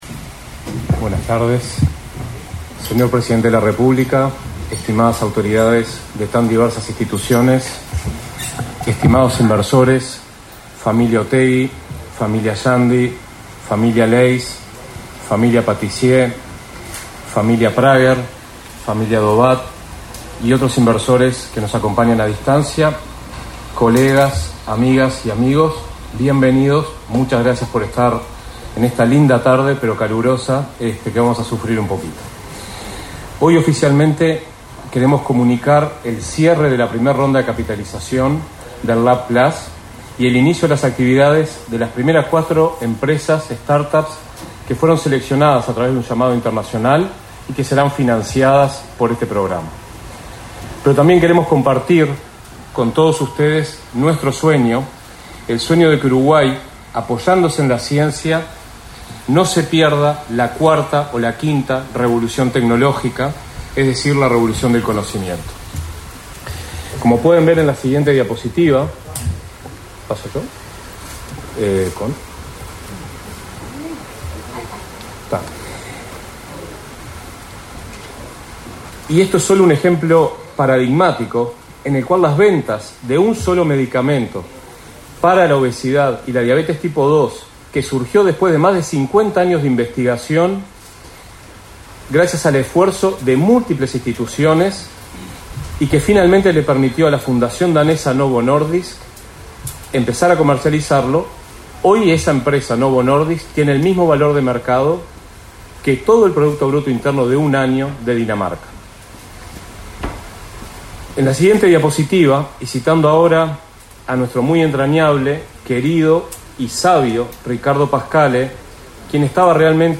Con la presencia del presidente de la República, Luis Lacalle Pou, se realizó, este 22 de febrero, el acto por el inicio a las actividades del Proyecto LAB+ del Instituto Pasteur de Montevideo.